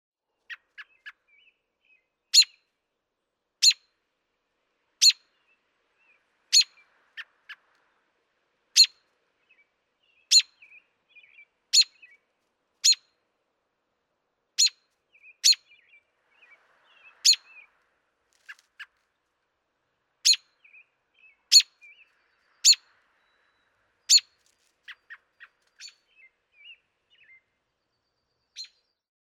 ♫19. Calls, sharp and agitated, in defense of a nest.
Mt. Greylock State Park, North Adams, Massachusetts.
019_American_Robin.mp3